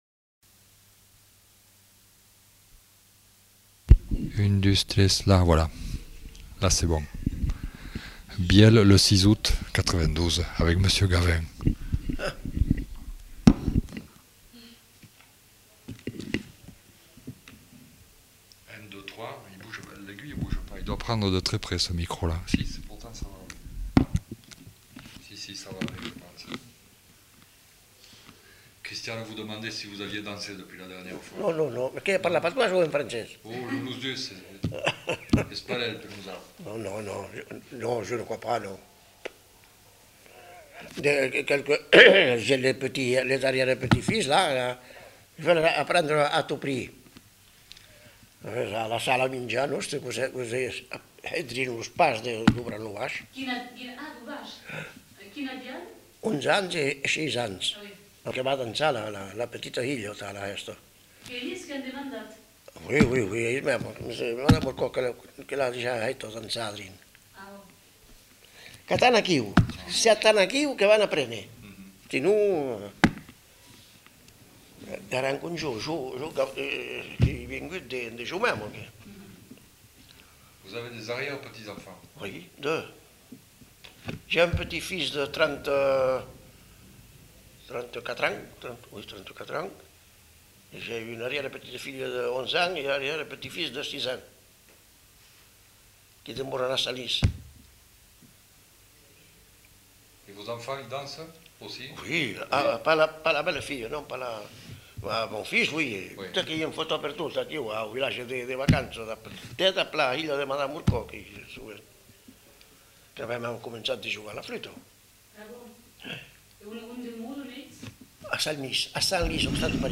Lieu : Bielle
Genre : témoignage thématique